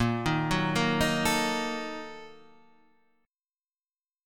A# Major 7th Suspended 4th Sharp 5th